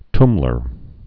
(tmlər)